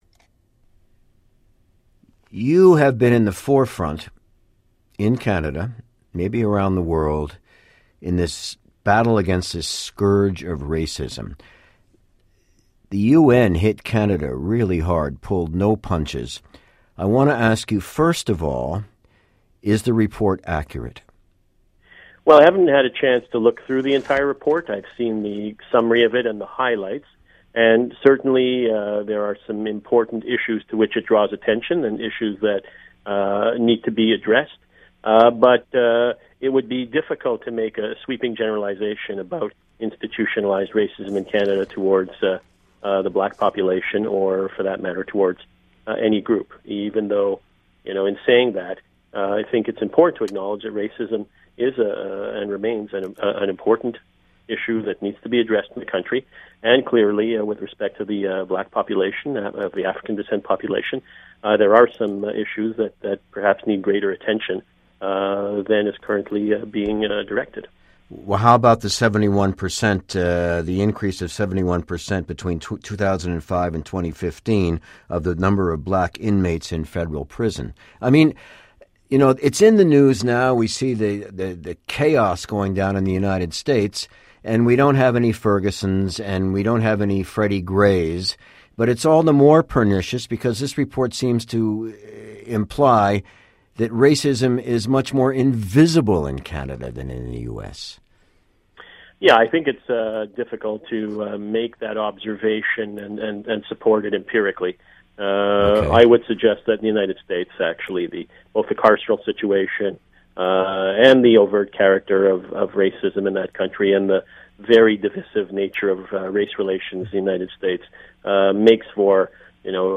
I spoke by phone with him at his Montreal office on Tuesday. Listen to the interview.